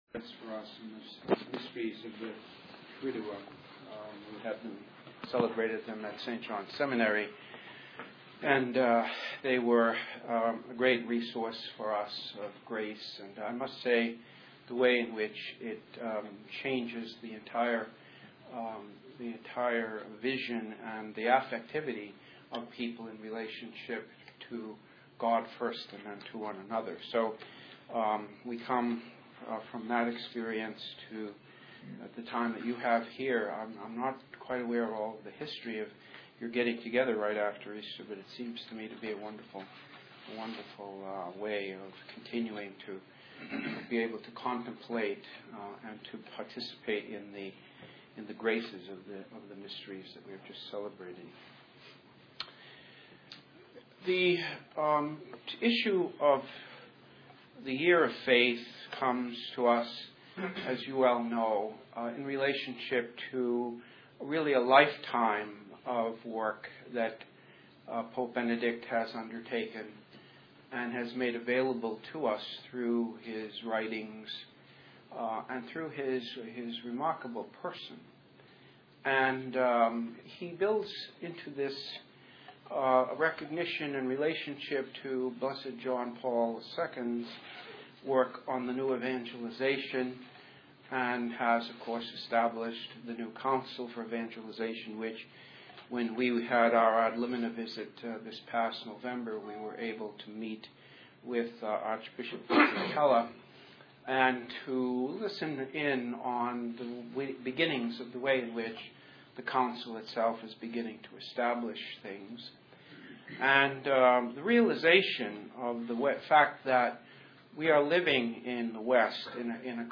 To listen to Bishop Arthur Kennedy’s talk on Priests, Porta Fidei and the Year of Faith , please click below.